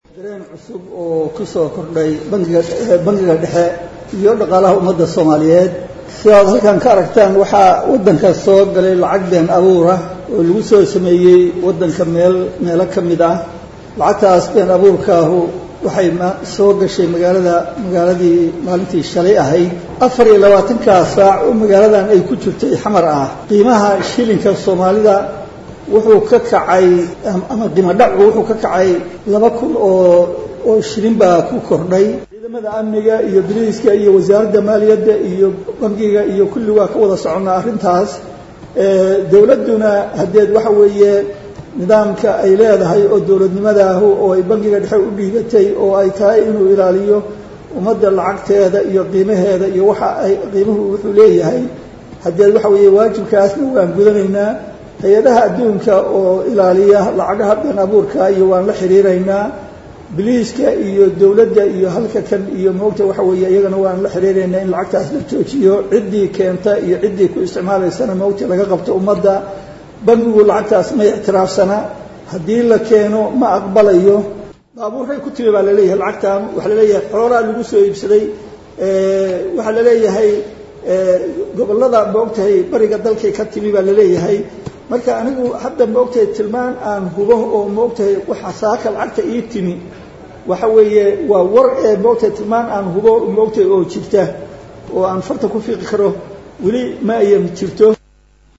Muqdisho(INO)-Guddoomiyaha Bangiga Dhexe ee Soomaaliya Bashiir Ciise Cali oo maanta shir jaraa’id Muqdisho ku qabtay ayaa sheegay in lacag been abuur ah oo lagu soo sammeeyay dalka Soomaaliya meelo ka mid ah ay shalay soo gaartay Magaalada Muqdisho, wuxuuna sheegay in ay lacagtaas keentay sicir-barar.